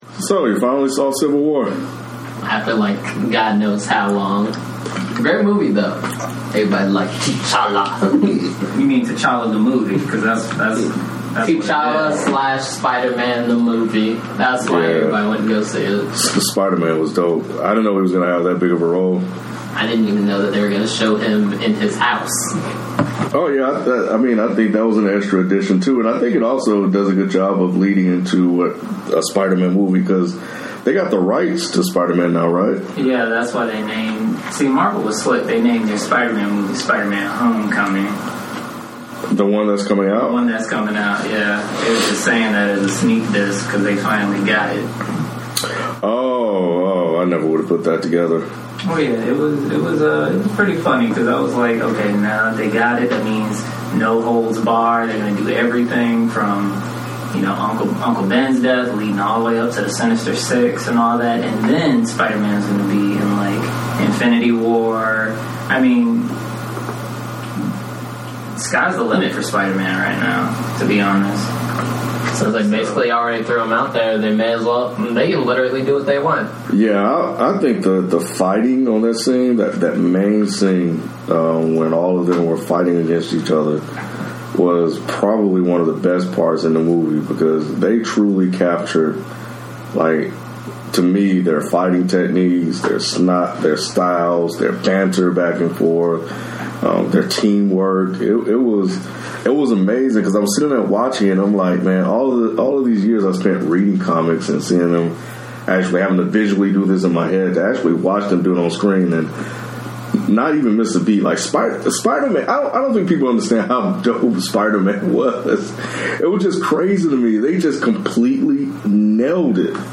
Here is a conversation on Marvel's latest blockbuster hit Captain America: Civil War. There are a few spoilers on here so enter at your own risk.